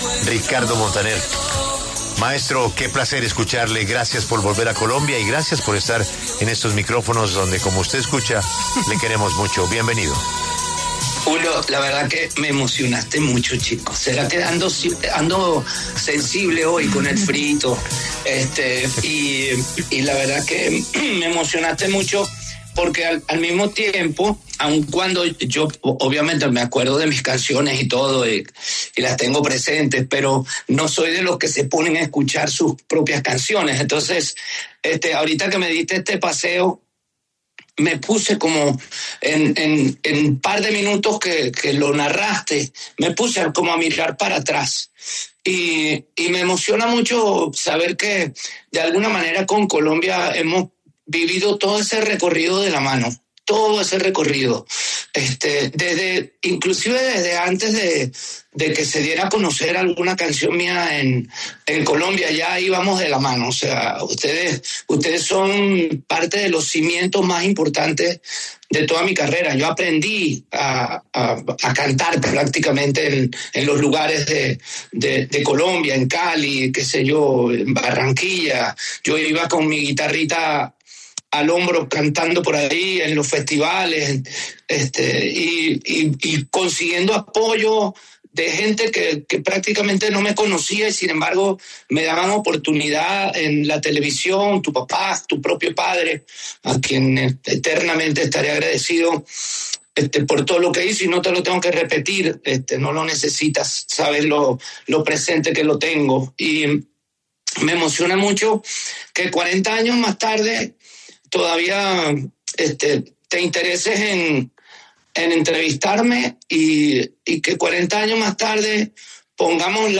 El cantante y compositor venezolano pasó por los micrófonos de W Radio para conversar sobre su carrera, sus éxitos y sus próximas presentaciones en Colombia para 2026 en el marco de ‘El último regreso tour’.